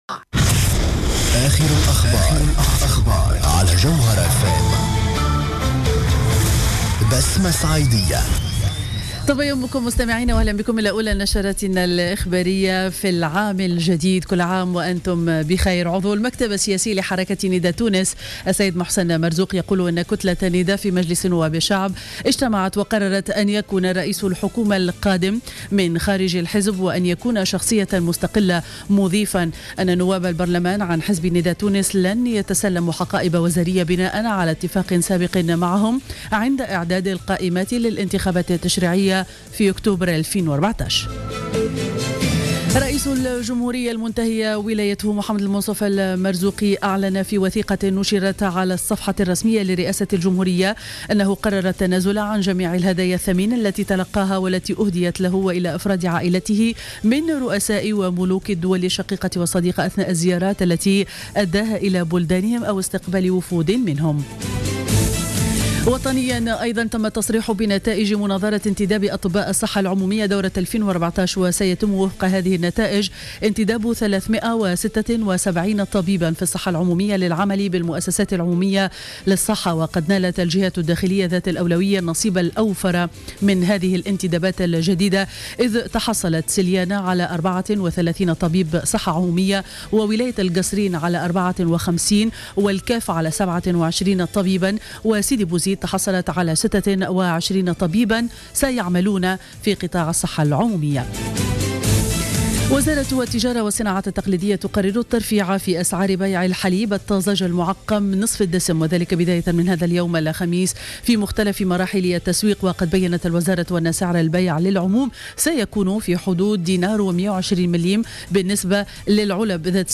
نشرة اخبار منتصف الليل ليوم الخميس 01 جانفي 2015